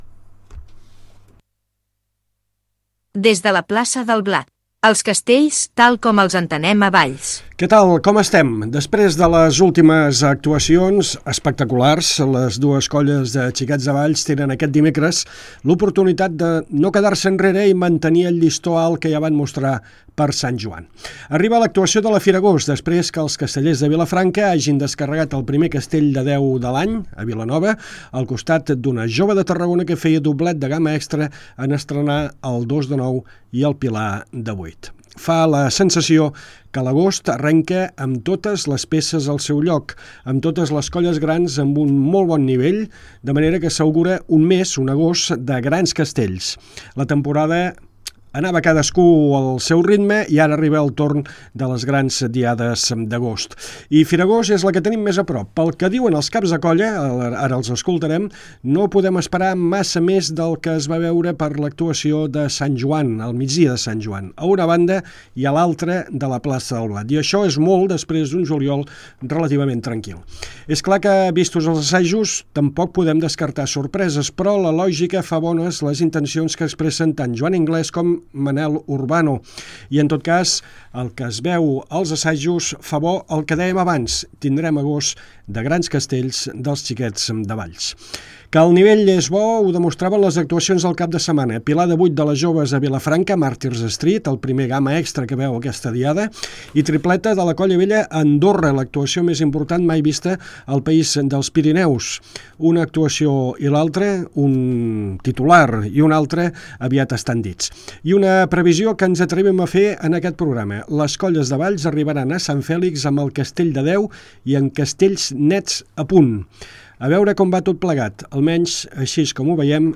Vuitena edició de Des de la Plaça del Blat, el programa que explica els castells tal com els entenem a Valls. Tertúlia amb la prèvia de la diada de la Firagost